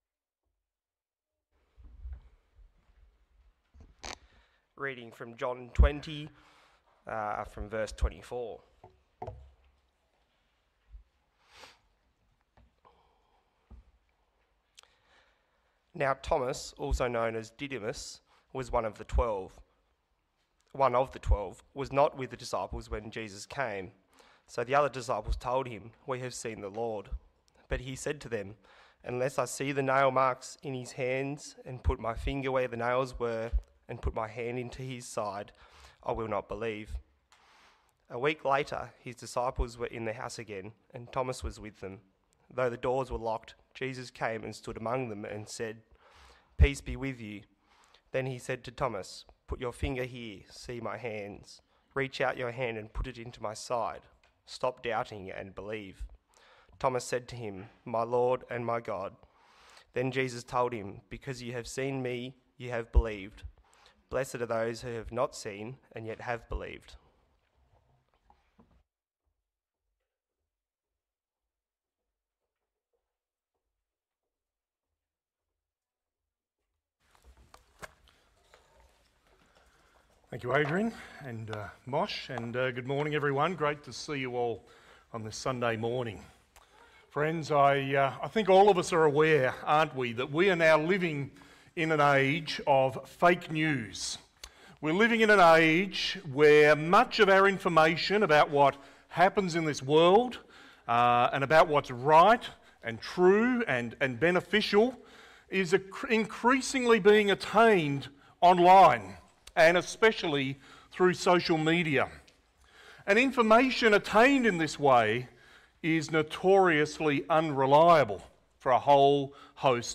Download Download Reference John 20:24-29 The Gospel According To John Current Sermon Fake News or True Facts?